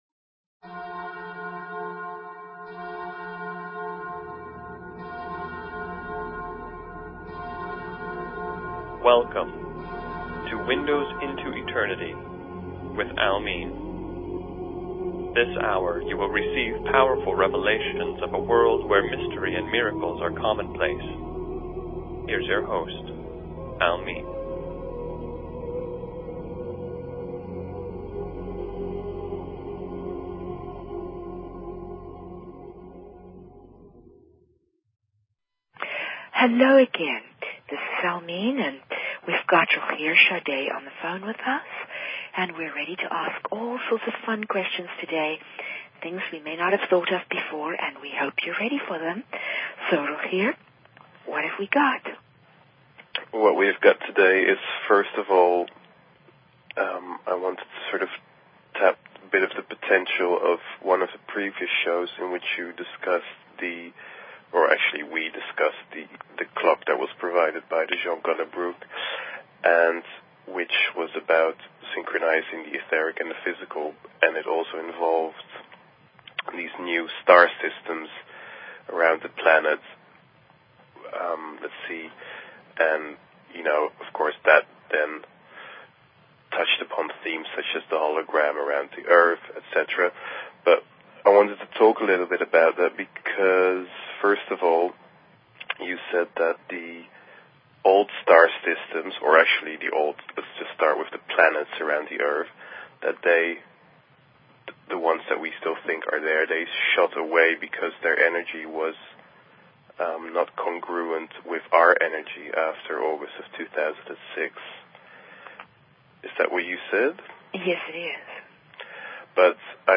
Talk Show Episode, Audio Podcast, Windows_Into_Eternity and Courtesy of BBS Radio on , show guests , about , categorized as